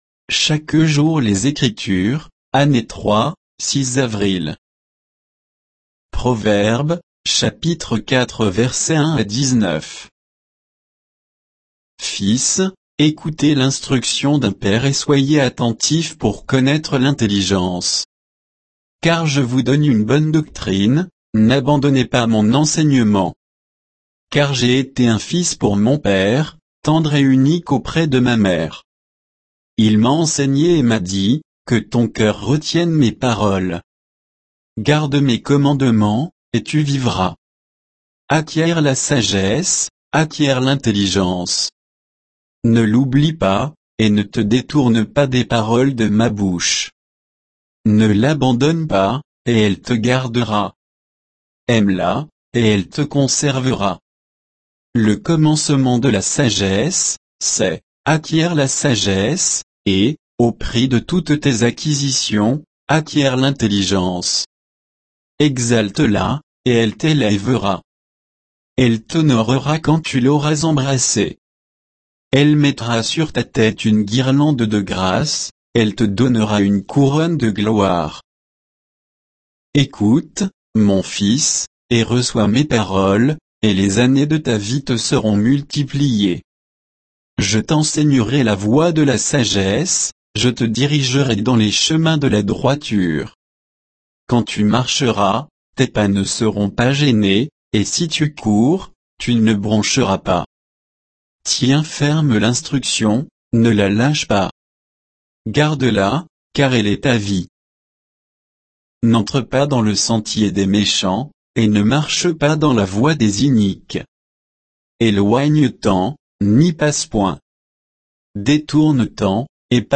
Méditation quoditienne de Chaque jour les Écritures sur Proverbes 4